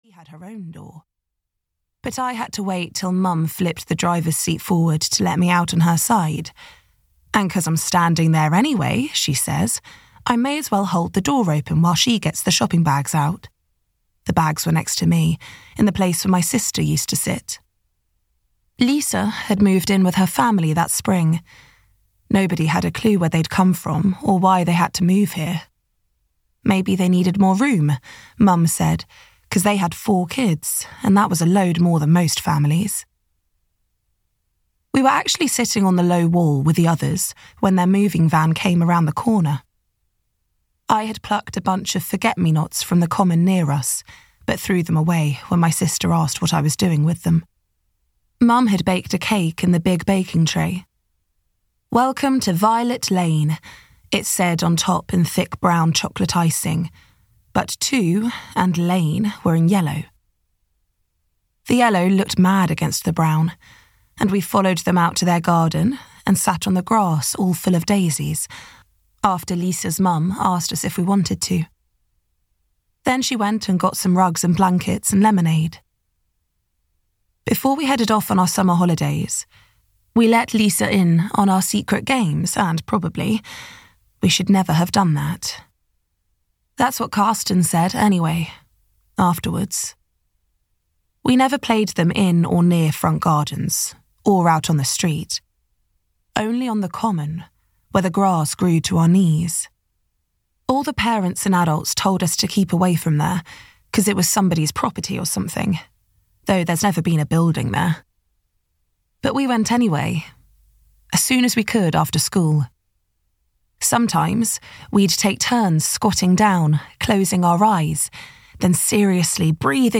The Reunion (EN) audiokniha
Ukázka z knihy